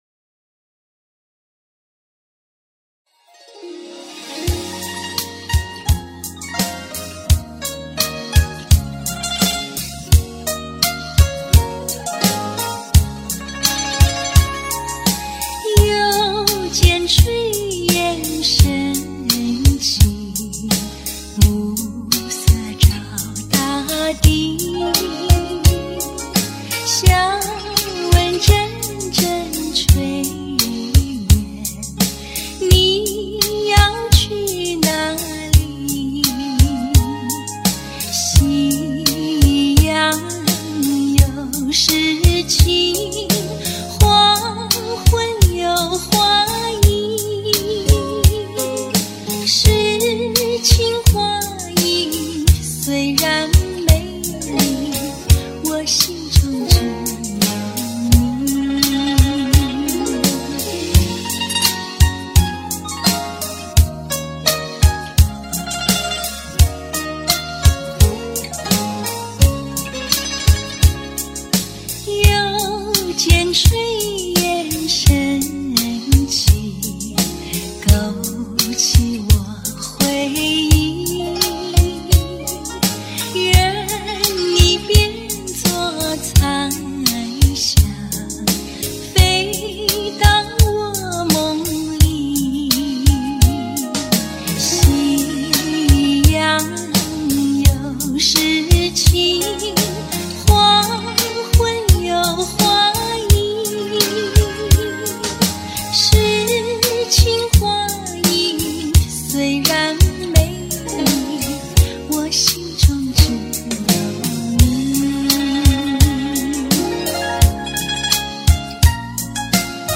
Category:Song